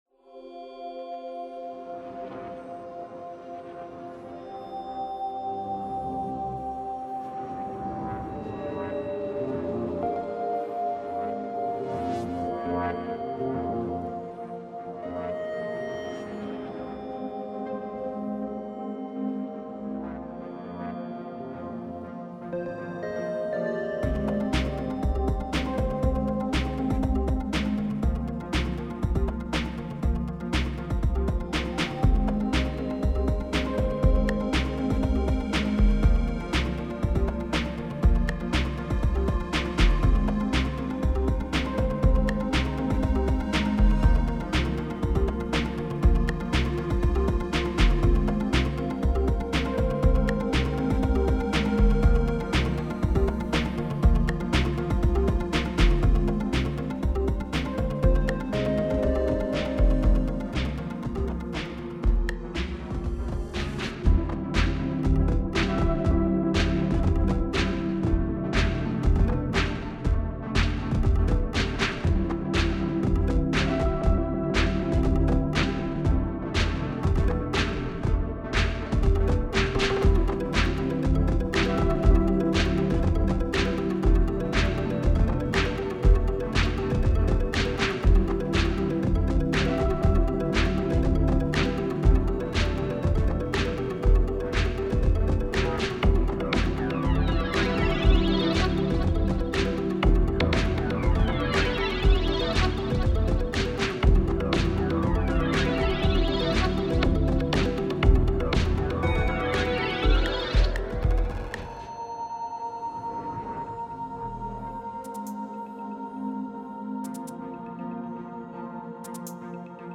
It was time to upgrade the digital synthesizers.